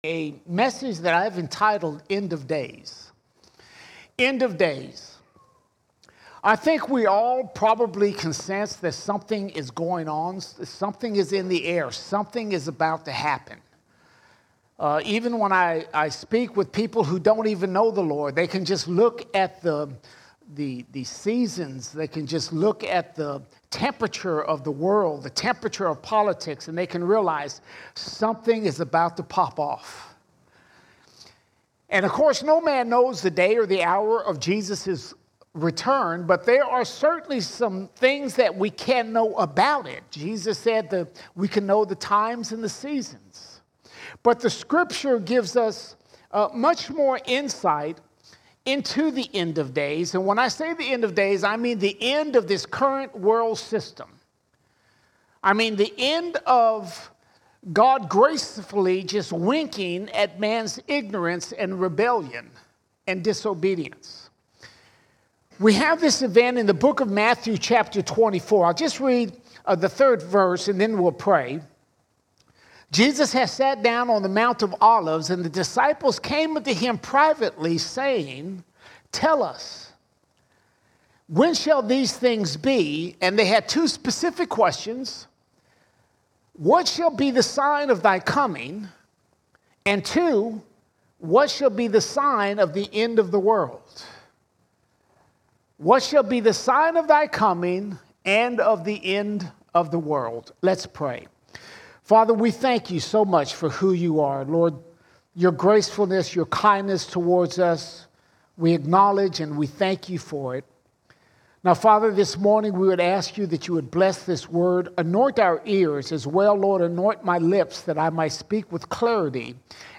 22 April 2024 Series: Sunday Sermons Topic: end time All Sermons End of Days End of Days We are told about the end of days by Jesus in Matthew 24.